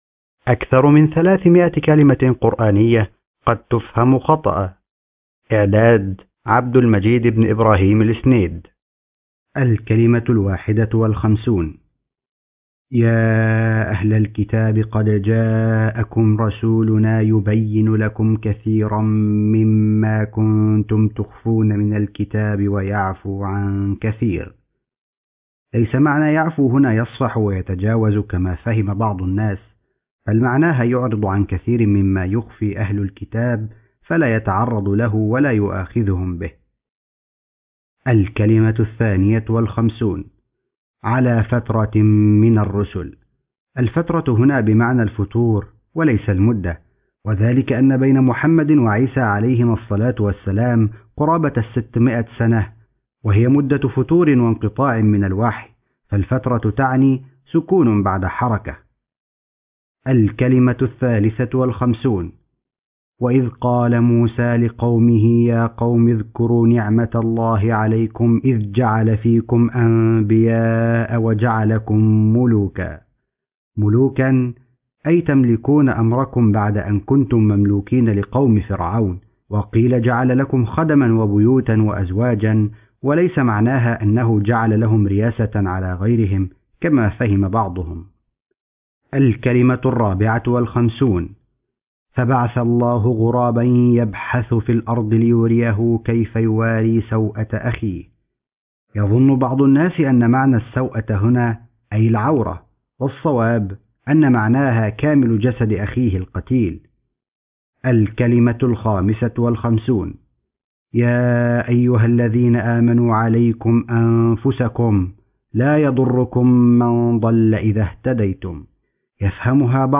أكثر من 300 كلمة قرآنية قد تفهم خطأ ( كتاب صوتي مقروء )